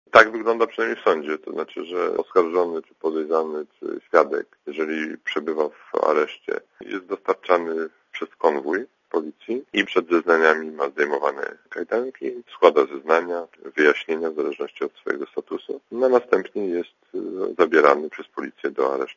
* Dla Radia ZET mówi Roman Giertych*